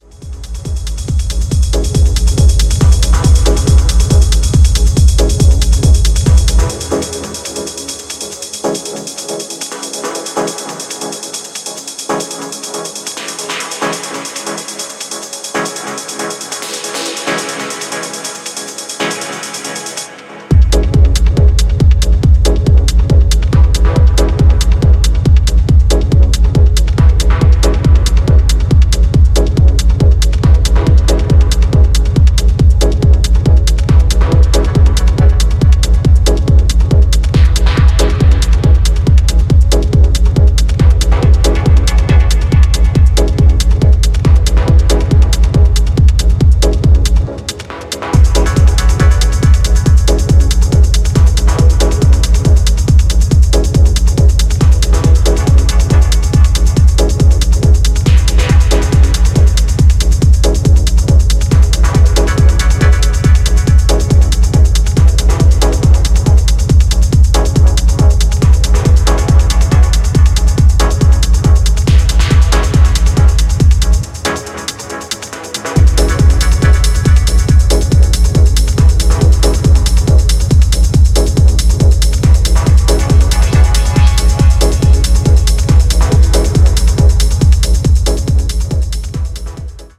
Dub Techno